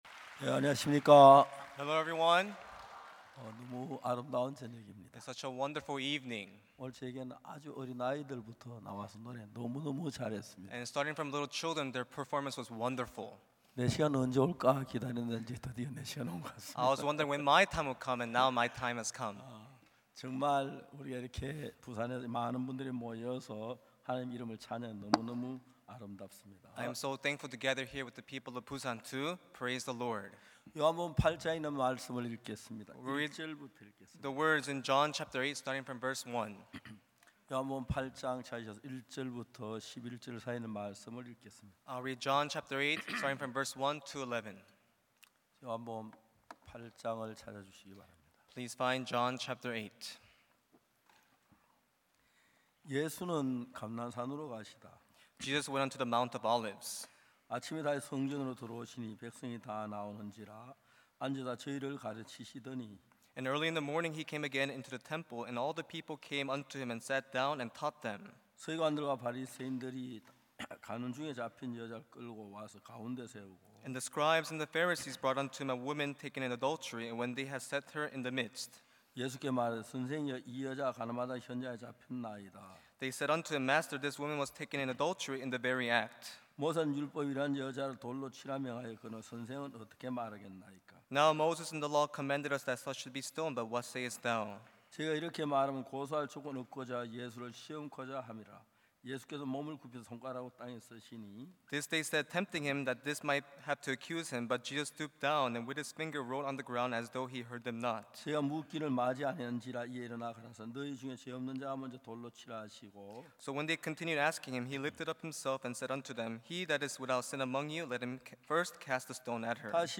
2024 후반기 부산 성경세미나